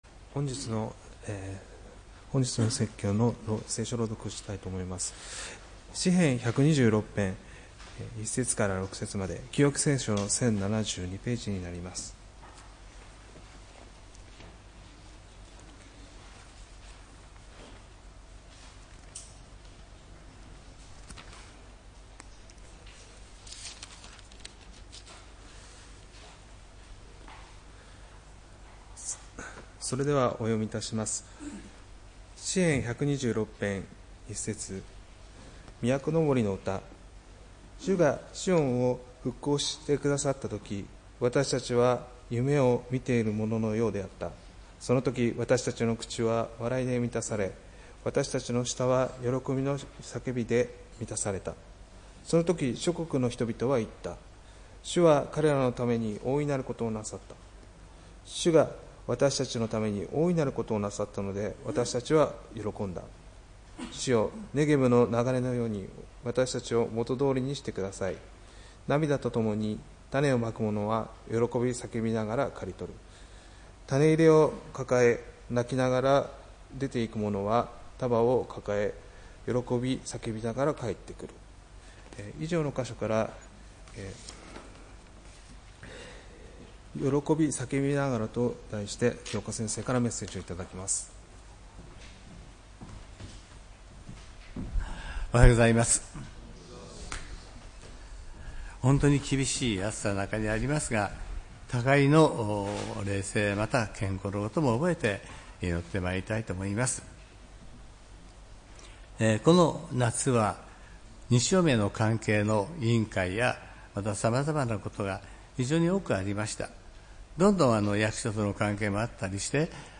礼拝メッセージ「喜び叫びながら」（８月31日）